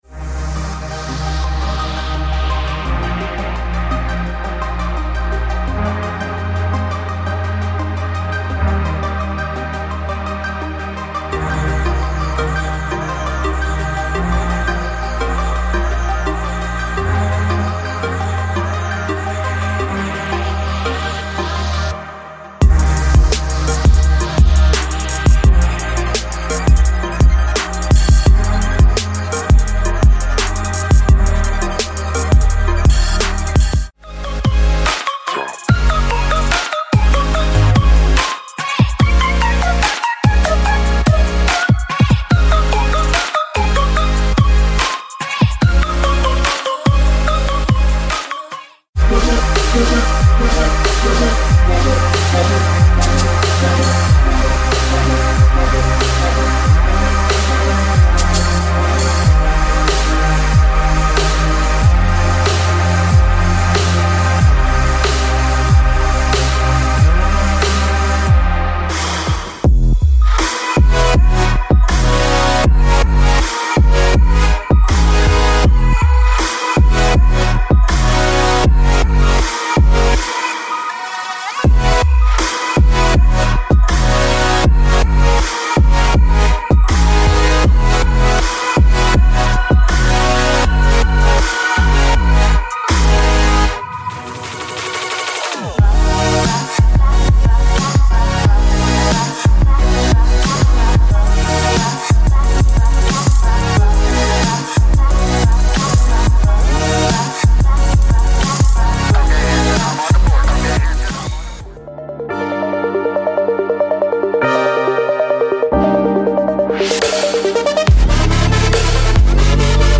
这些可即时使用的和弦，主音，踩hat和小军鼓的速度为90 BPM，可节省宝贵的生产时间。
• 该软件包包含大约950多个高质量MIDI文件，包括和弦进行曲（打击垫），弹拨，琶音，主音，鼓，军鼓和低音Midis。
• 查看上面的演示播放，以了解和弦的声音。
• MIDI的组织和命名有两个主键：A-Minor和C-Major。